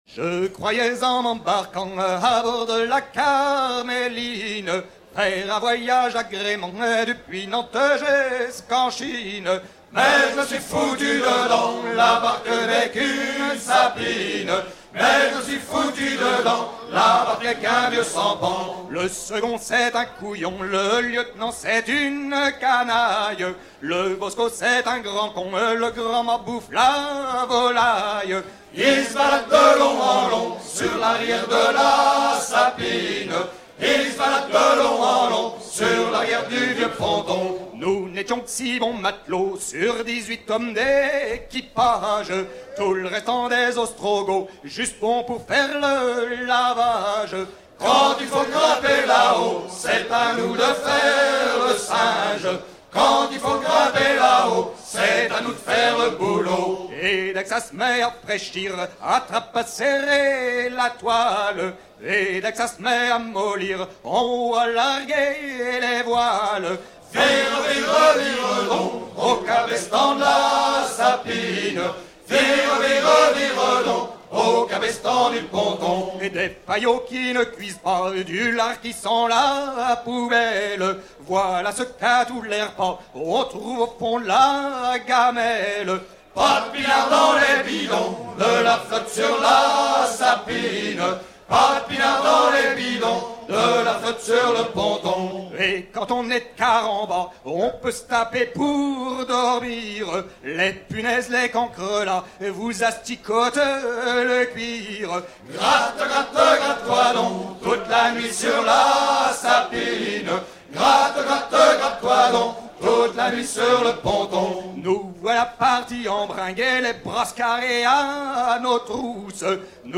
à virer au cabestan
circonstance : maritimes
Genre strophique